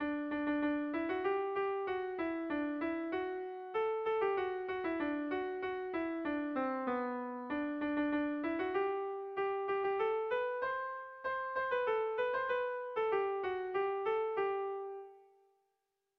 Bertso melodies - View details   To know more about this section
Zortziko txikia (hg) / Lau puntuko txikia (ip)
ABA2D